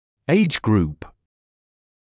[eɪʤ gruːp][эйдж груːп]возрастная группа